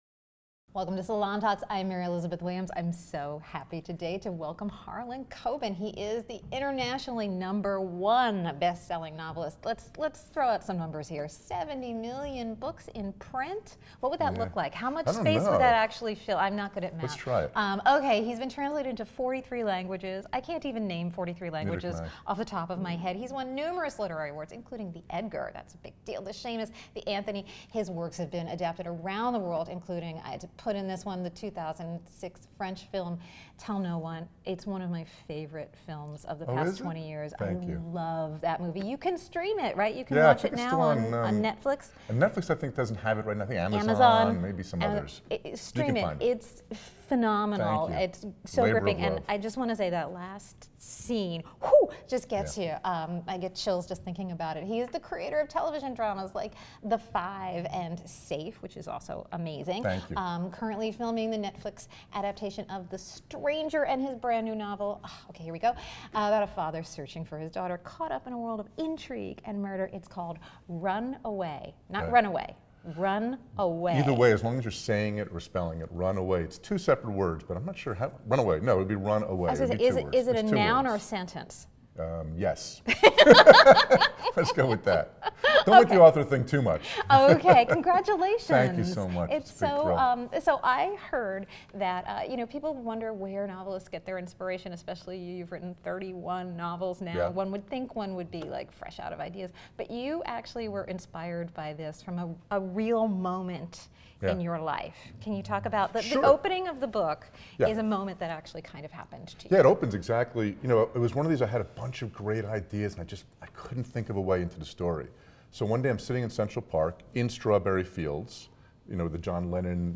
Number one NYT bestselling author Harlan Coben has sold millions of books, been translated into 43 languages, and had his works adapted into film and television shows around the world. Yet the New Jersey native, whose newest novel is the twisty thriller "Run Away," never takes success as a sure thing, he shared on “Salon Talks.”